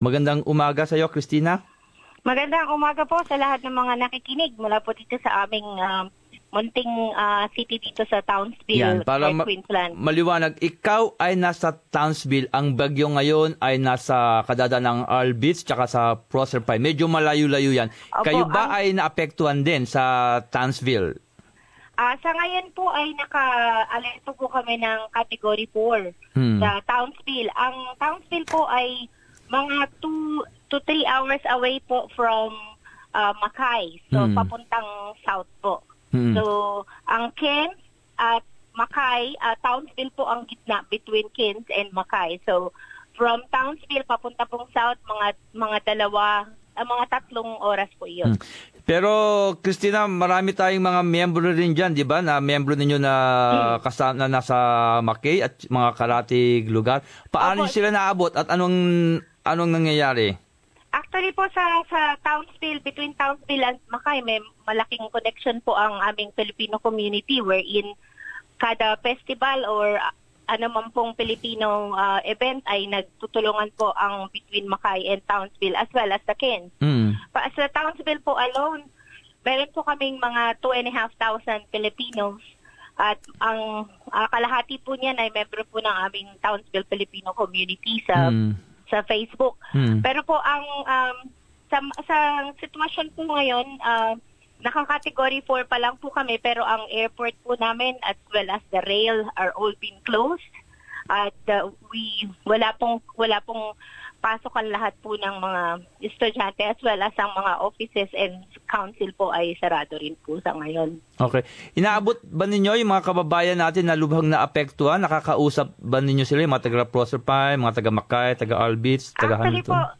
In separate interviews